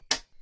HitWood3.wav